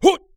人声采集素材/男3战士型/ZS发力3.wav